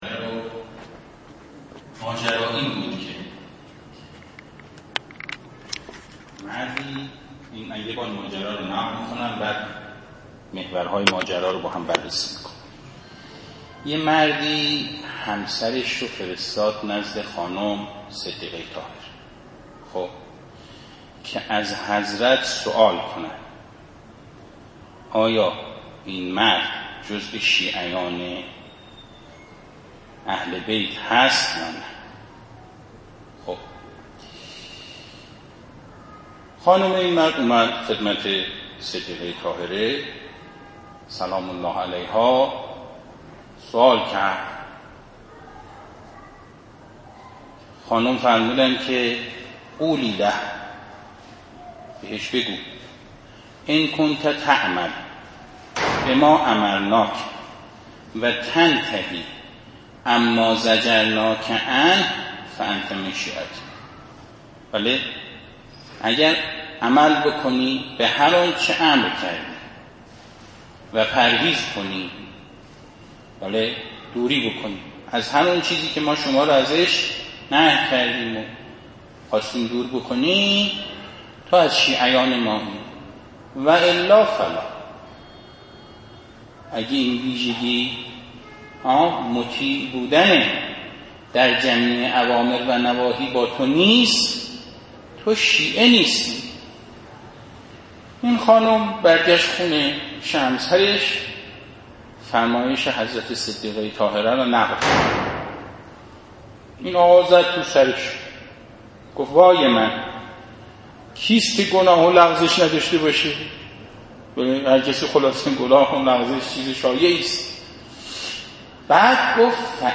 ⚫ جلسه خانوادگی هیئت شهدای گمنام مدرسه امام حسین علیه السلام
⚫مجلس عزای مادر سادات حضرت زهرا سلام الله علیها